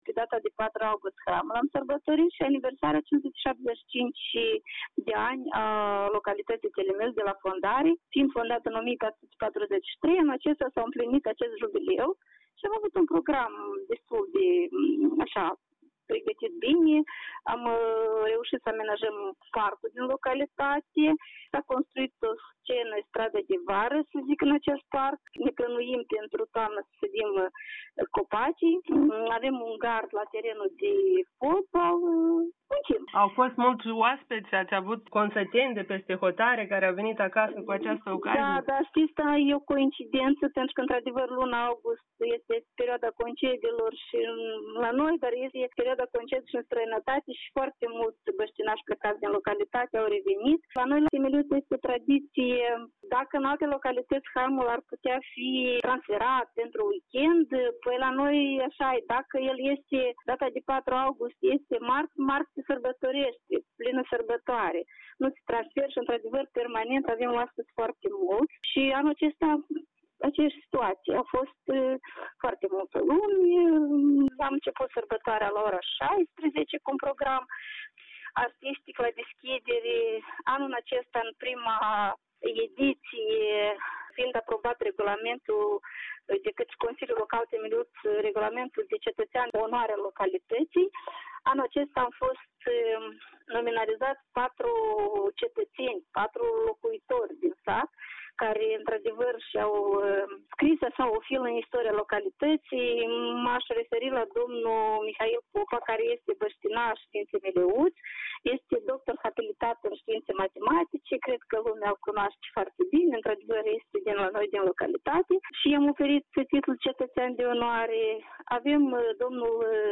Primarul localității Zinaida Țurcan a vorbit în cadrul unui interviu pentru calm.md despre schimbările ce au loc